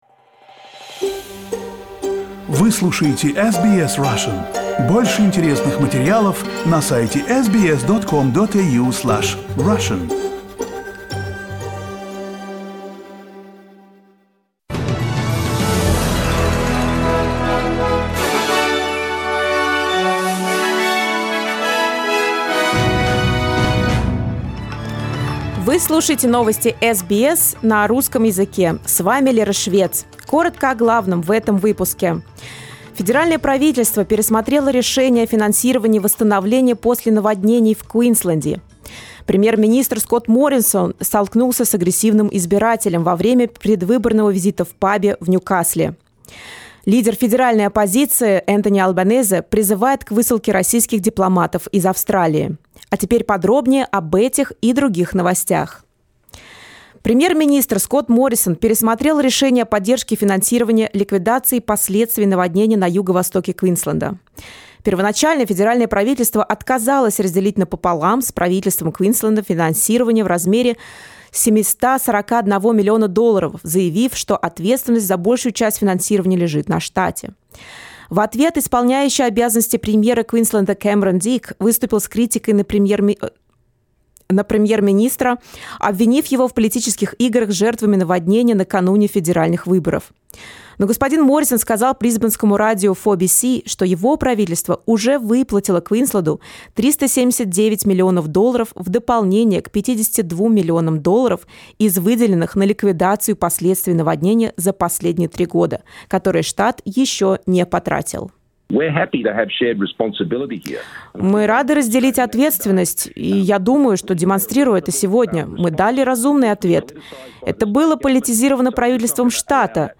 SBS news in Russian — 07.04